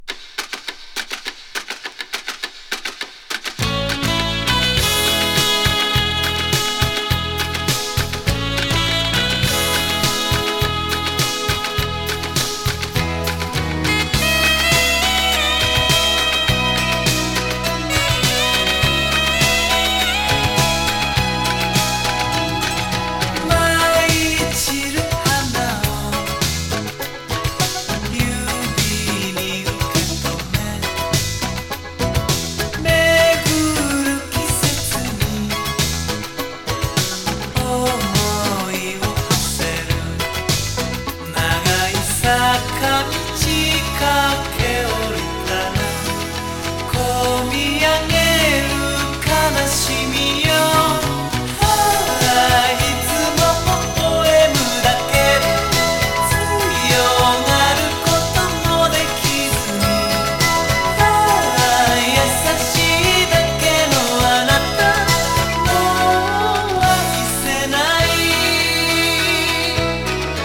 80's ロック / ポップス